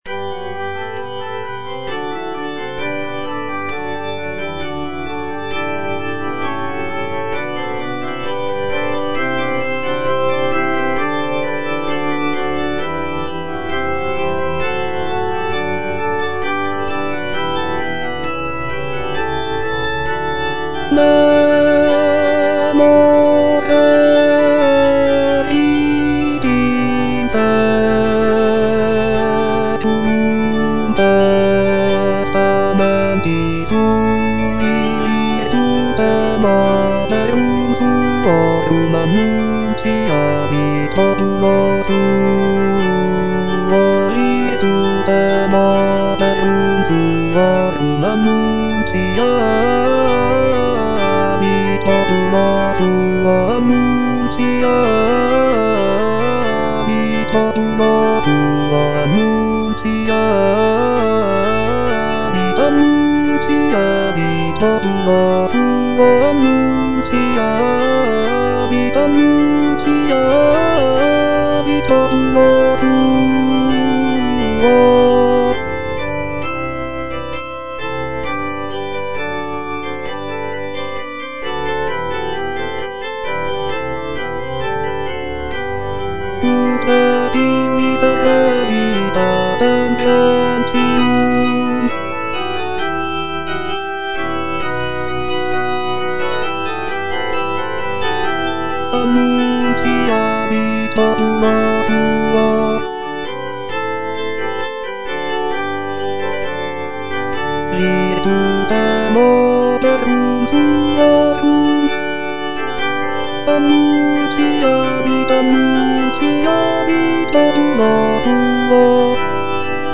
M.R. DE LALANDE - CONFITEBOR TIBI DOMINE Memor erit in saeculum (baritone) - Bass (Voice with metronome) Ads stop:
"Confitebor tibi Domine" is a sacred choral work composed by Michel-Richard de Lalande in the late 17th century.
Lalande's composition features intricate polyphony, lush harmonies, and expressive melodies, reflecting the Baroque style of the period.